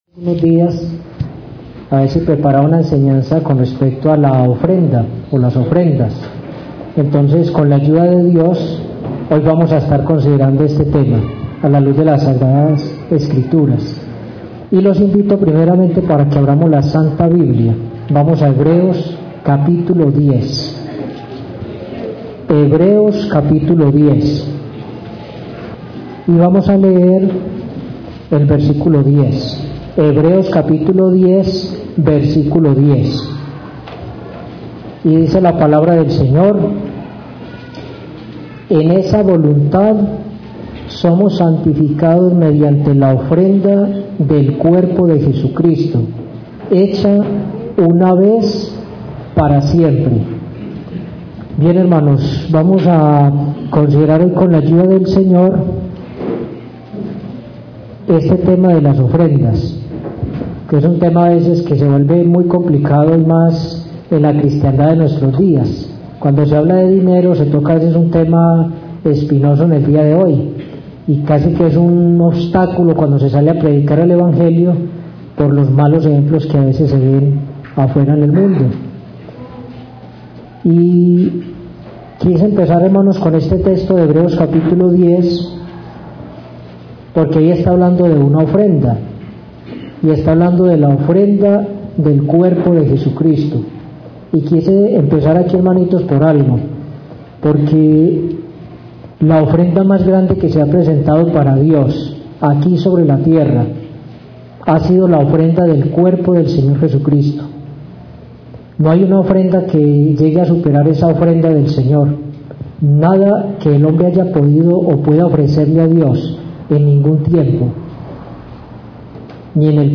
Una serie de enseñanzas que tratan sobre el desarrollo y apoyo de la Misión de la Iglesia de Jesucristo.